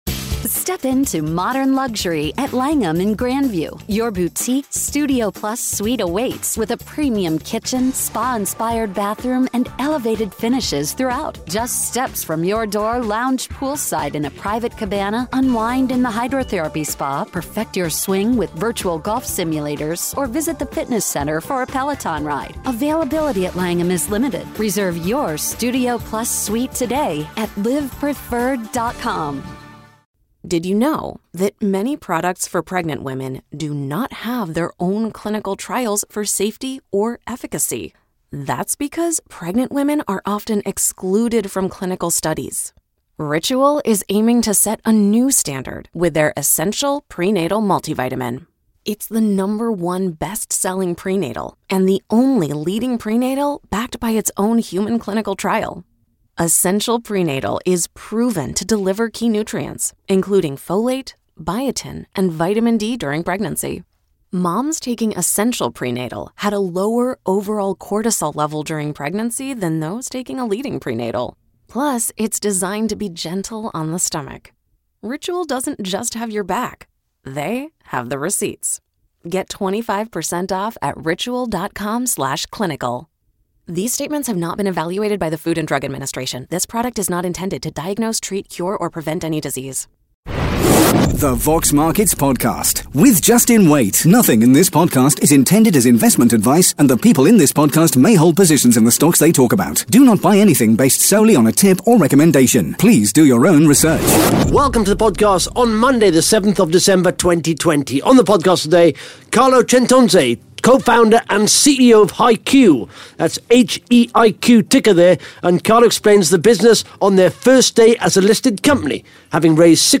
(Interview starts at 11 minutes 48 seconds)